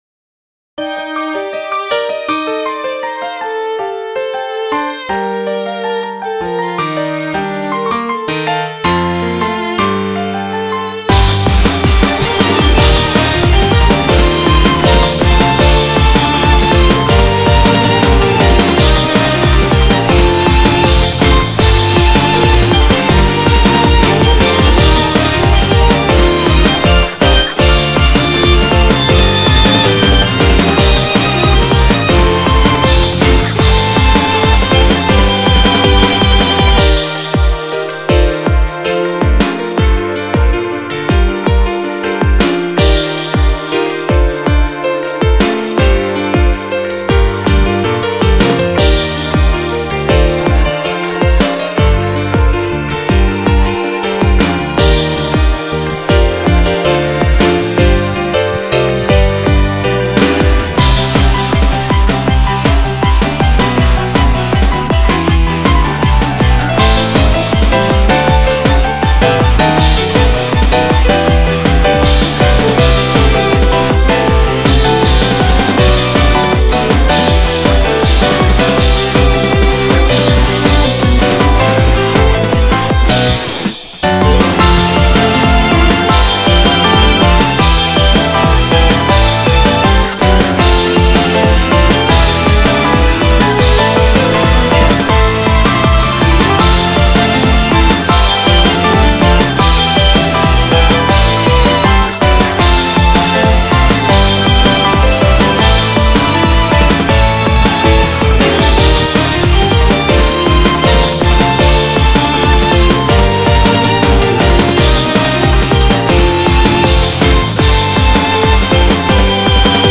（Vocaloid用楽曲）
というかなりセツナイ曲
コーラス有りオケ
（Tempo=160）